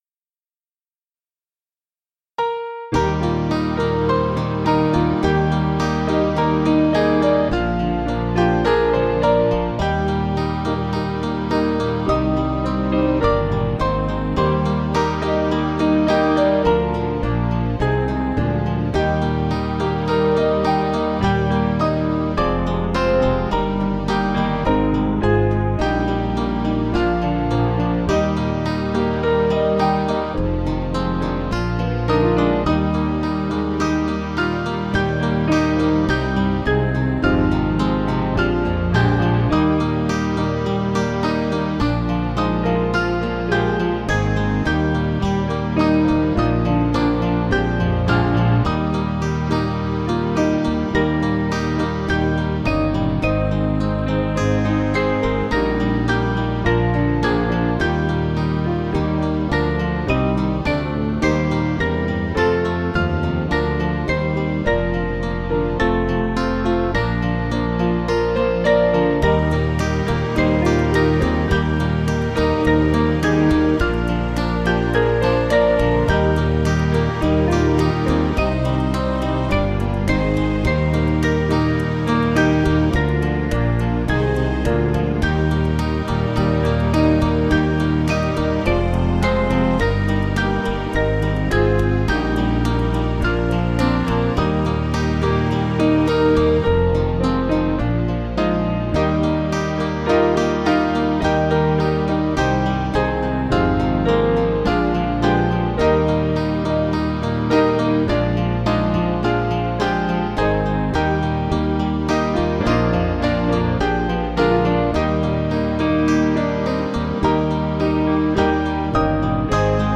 Small Band
(CM)   3/Eb 488.5kb